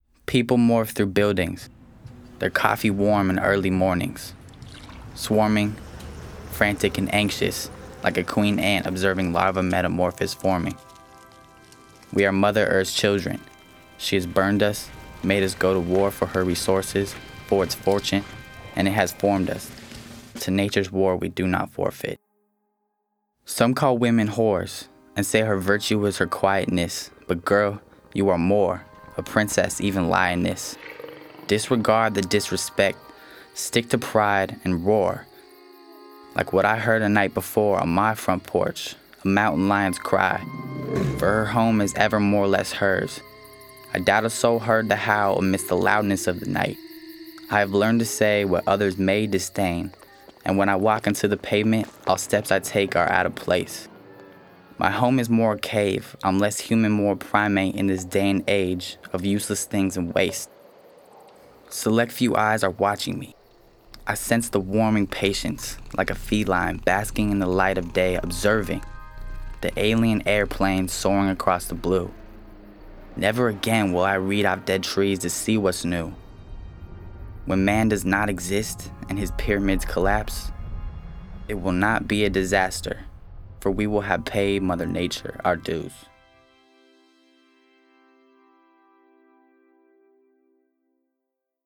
In this audio soundscape